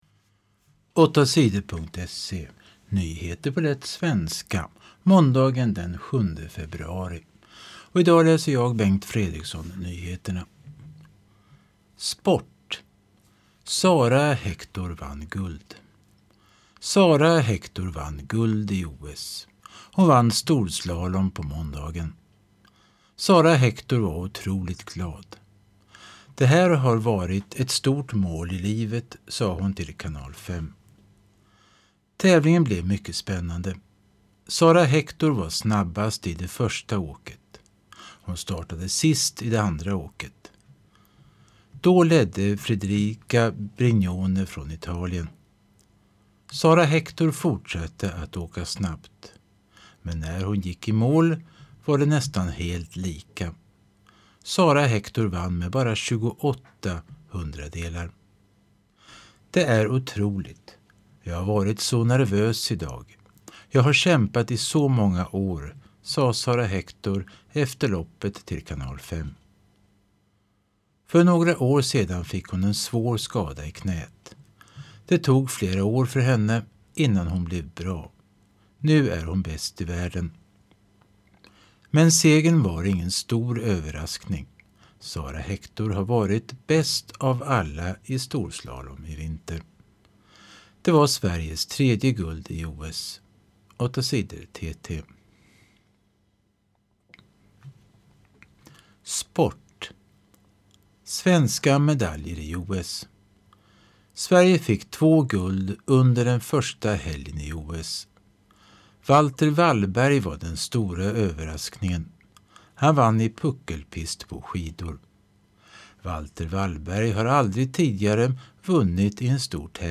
Lyssnar på nyheter från 2022-02-07.